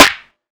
SNARE.26.NEPT.wav